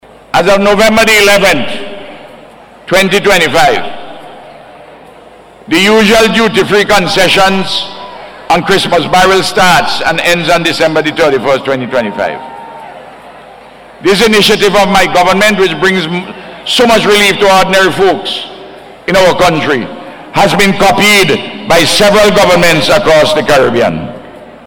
He made the announcement during his Independence Day Address on Monday evening.